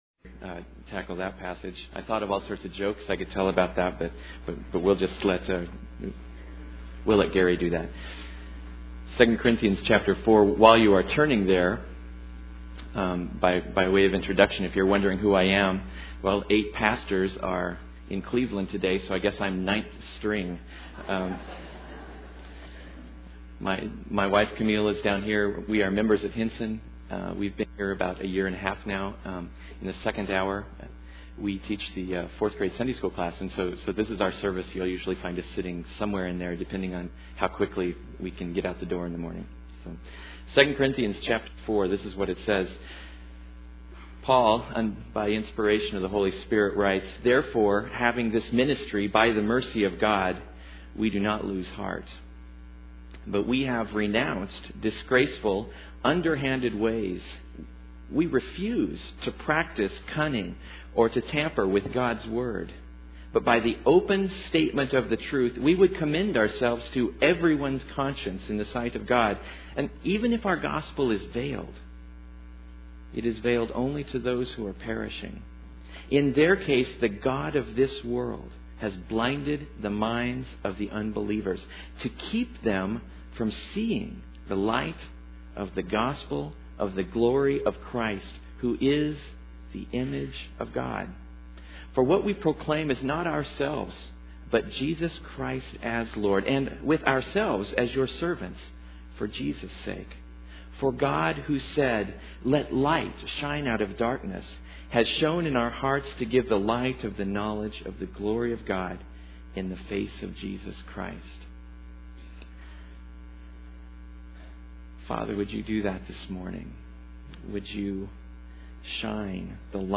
Hinson Church Audio Sermons